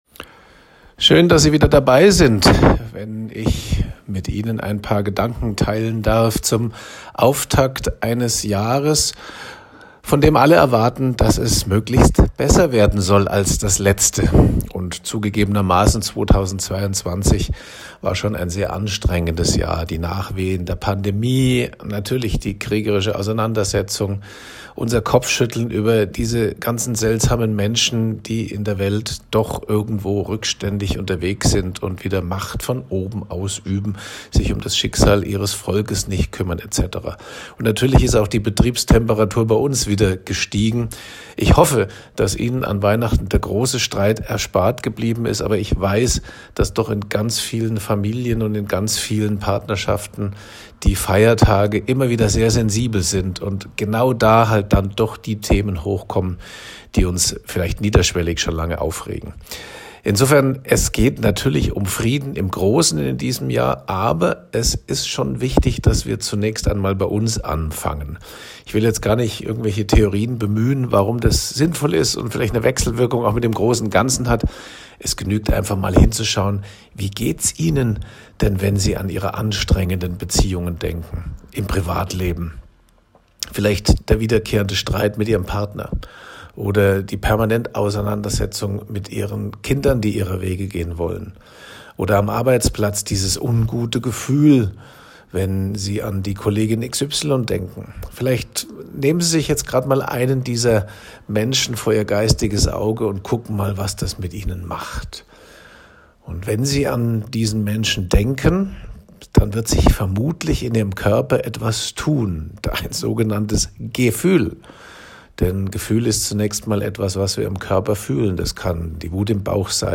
Zeitloser Talk zum MEFISTO-Tool